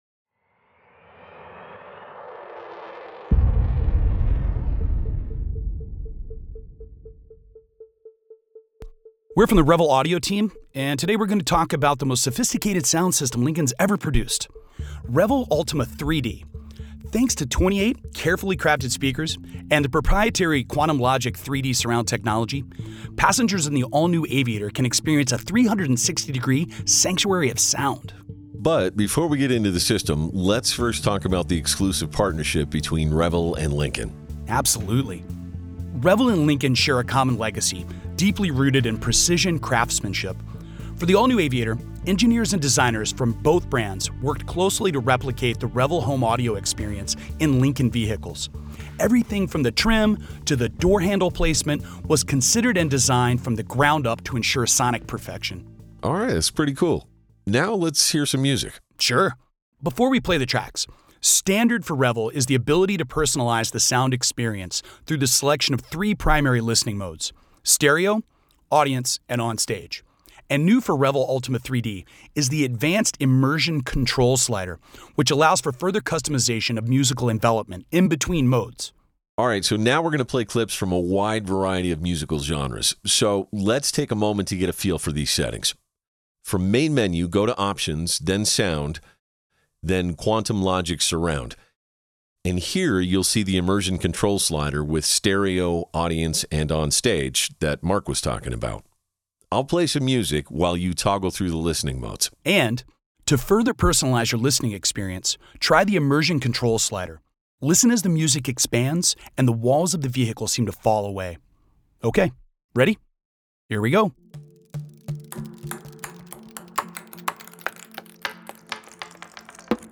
REVEL AND LINCOLN—AN IMMERSIVE AUDIO TOUR Immersive Audio Track Entertains and Educates Media Personnel on Luxury Audio System CHALLENGE For nearly 20 years, Revel and Lincoln have collaborated closely to fuse the art of luxury with the science of sound.